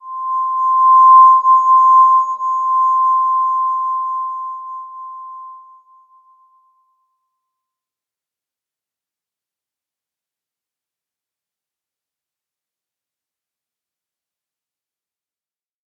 Slow-Distant-Chime-C6-p.wav